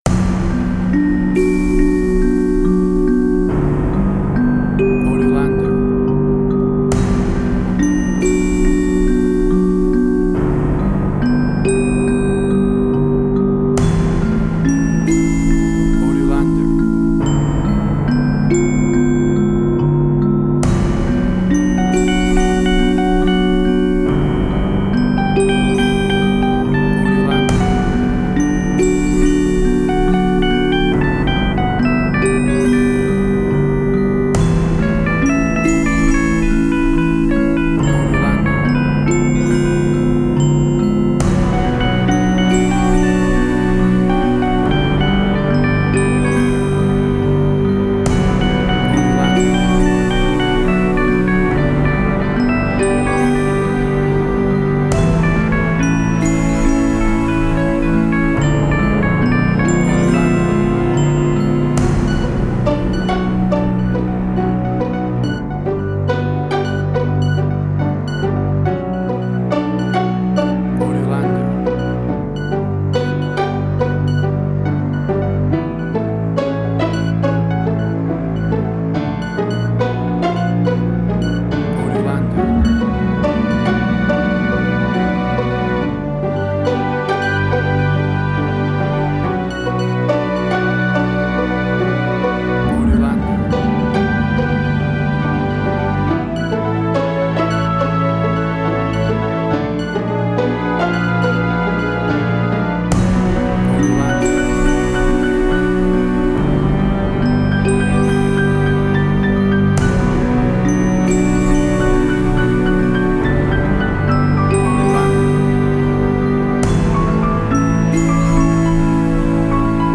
Indie Quirky
Tempo (BPM): 70